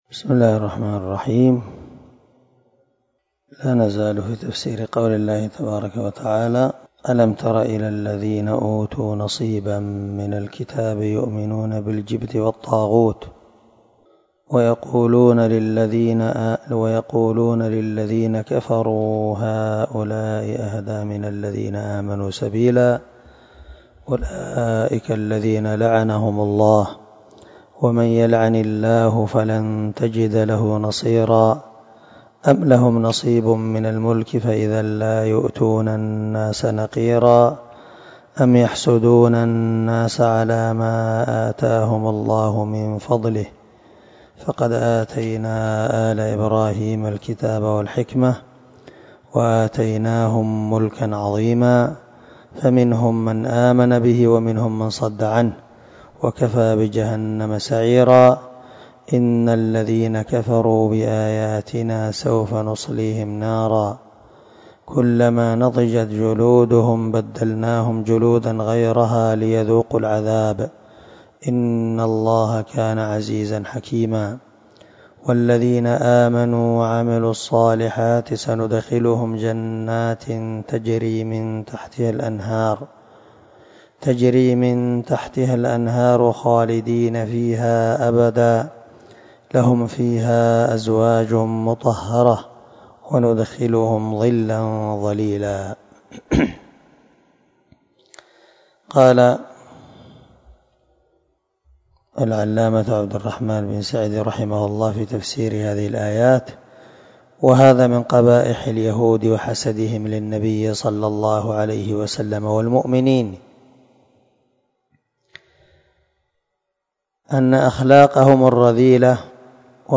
272الدرس 40 تابع تفسير آية ( 51 – 57 ) من سورة النساء من تفسير القران الكريم مع قراءة لتفسير السعدي